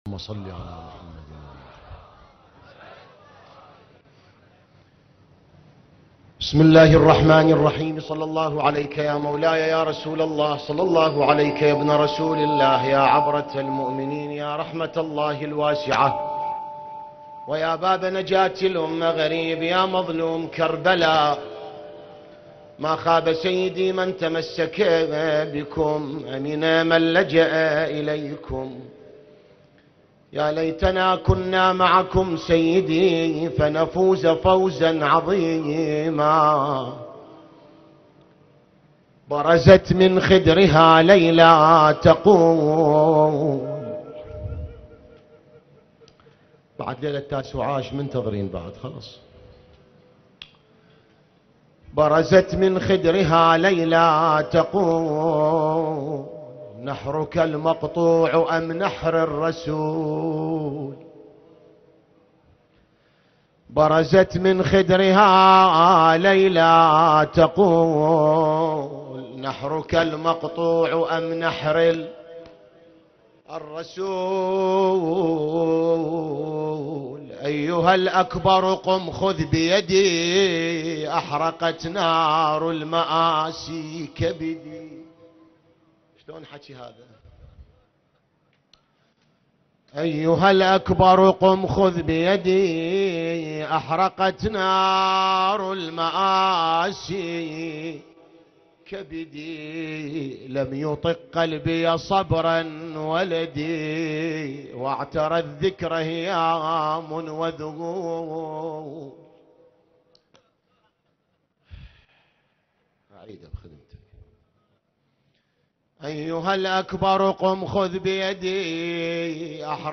نعي مصيبة وشهادة علي الأكبر ع النجف الأشرف